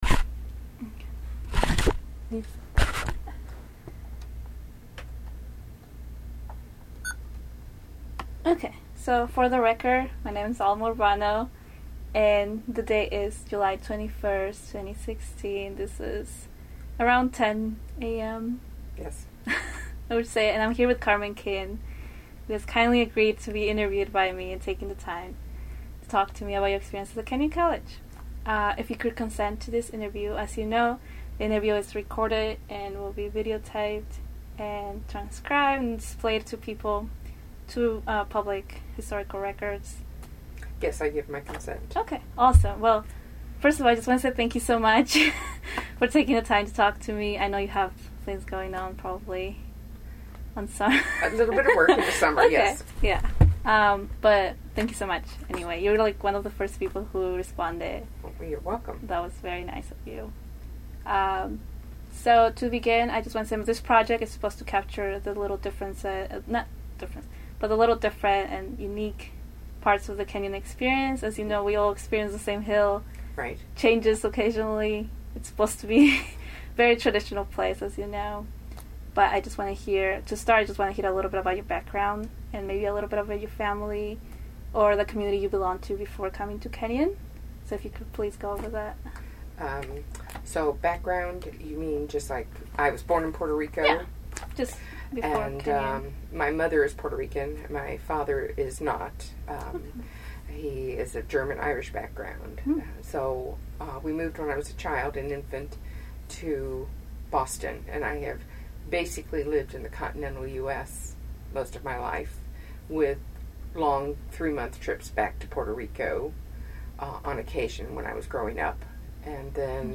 Oral History in the Liberal Arts | Latinx on the Hill